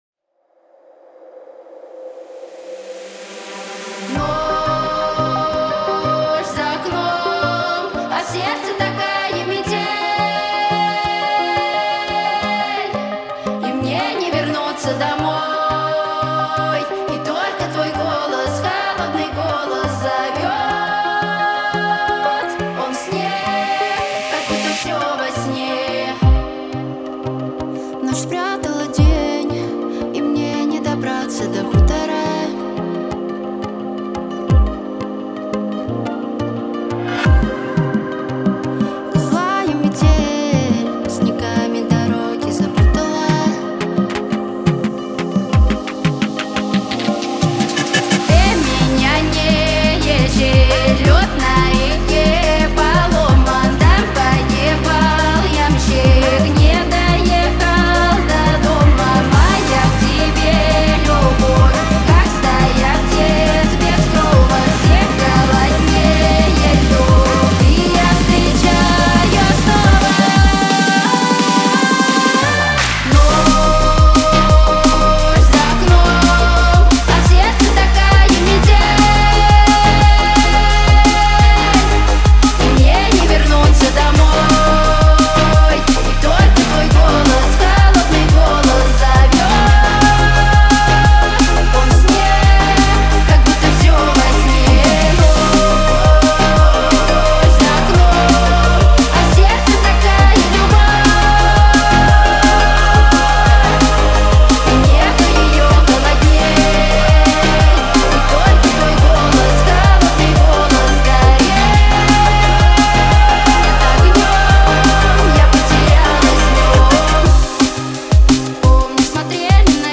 Дип хаус